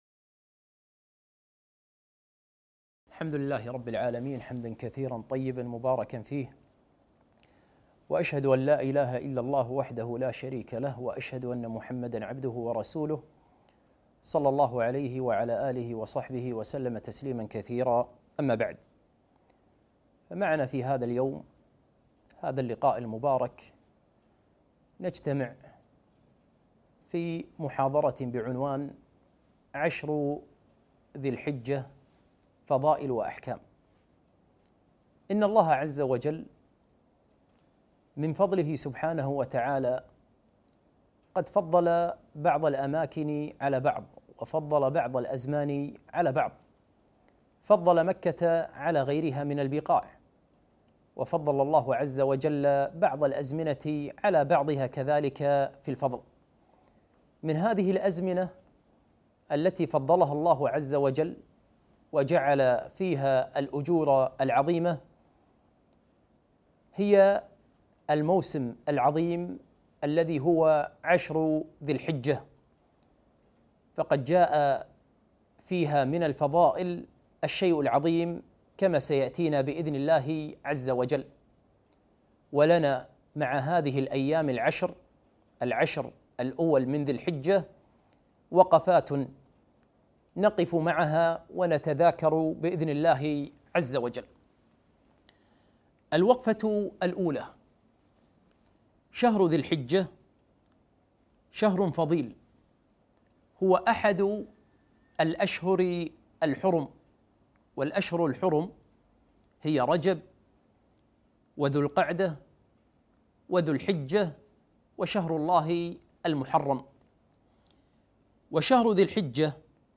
محاضرة - عشر ذي الحجة فضائل وأحكام